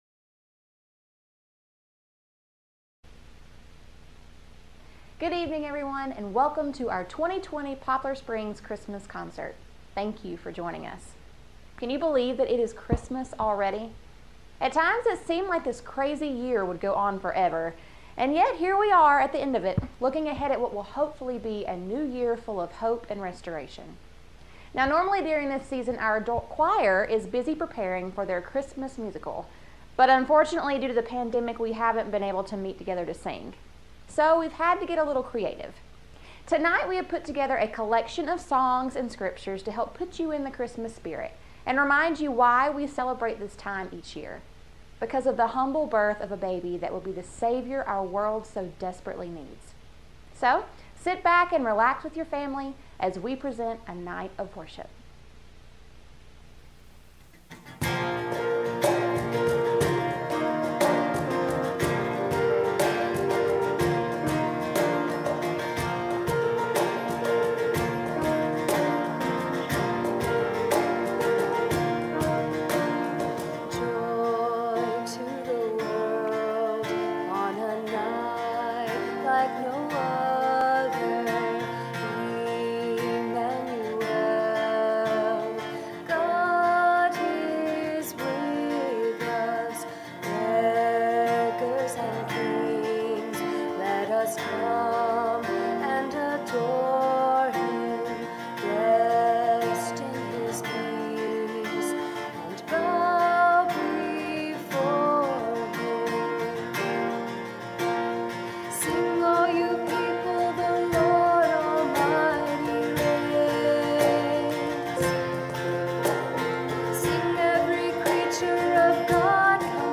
2020 Christmas Concert